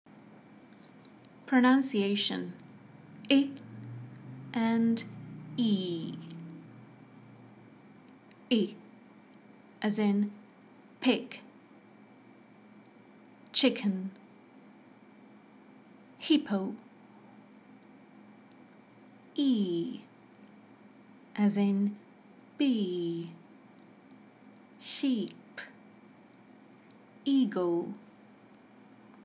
How are the words pronounced? Listen and repeat.